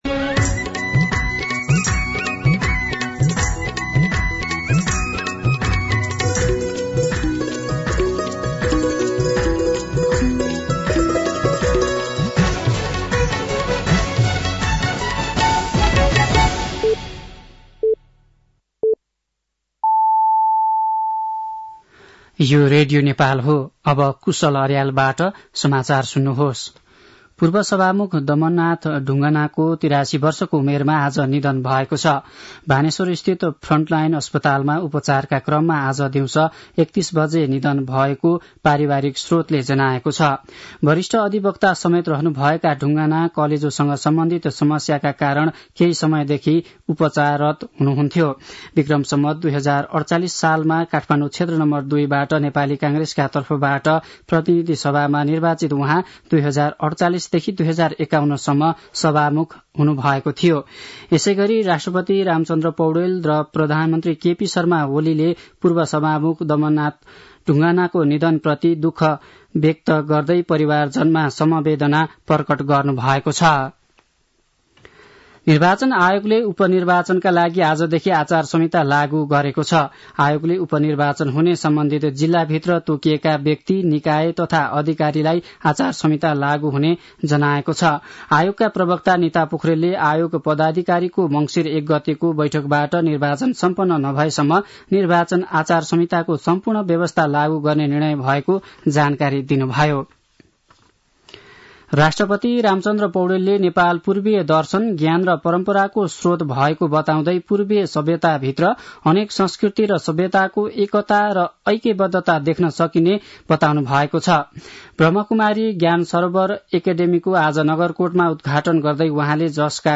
साँझ ५ बजेको नेपाली समाचार : ३ मंसिर , २०८१
5-PM-Nepali-News-8-2.mp3